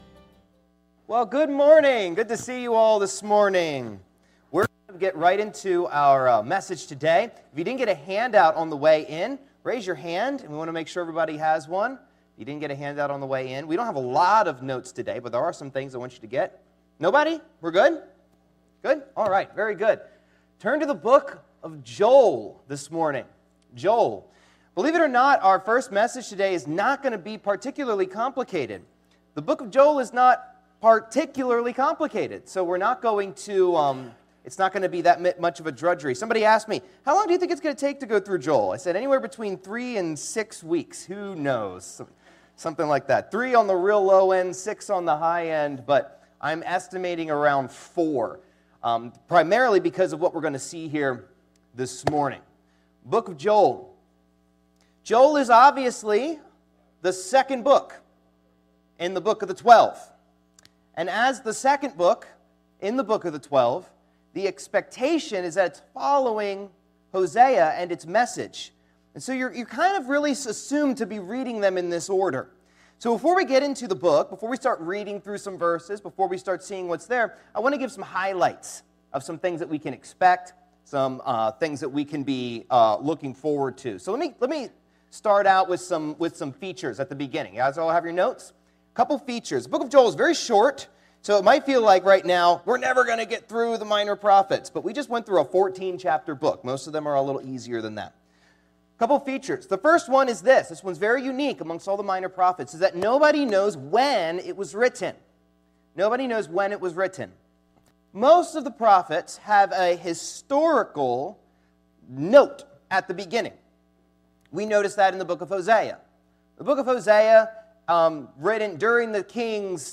Passage: Joel 1:1 Service Type: Auditorium Bible Class